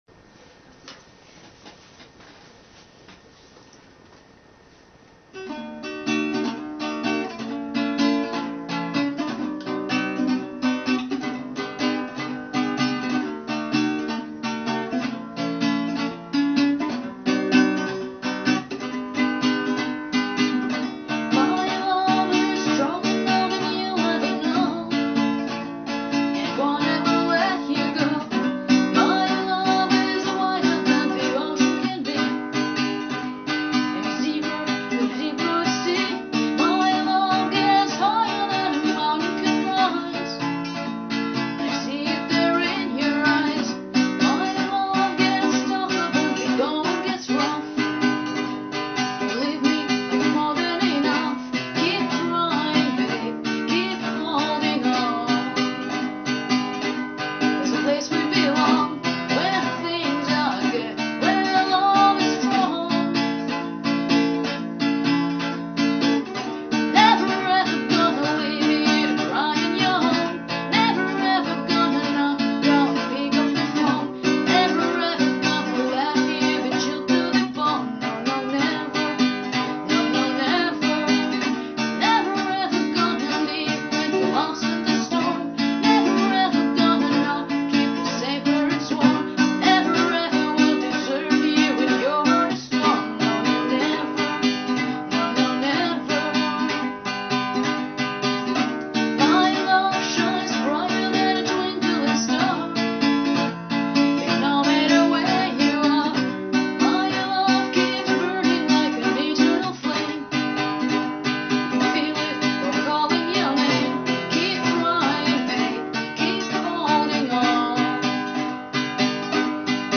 Cover.
A bit dark.. smile